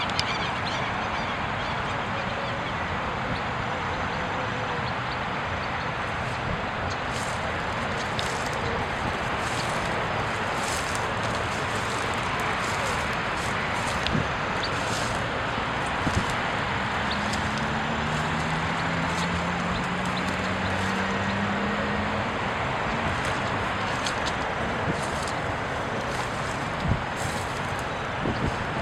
Remolinera Común (Cinclodes fuscus)
Nombre en inglés: Buff-winged Cinclodes
Localidad o área protegida: Termas de Río Hondo
Condición: Silvestre
Certeza: Vocalización Grabada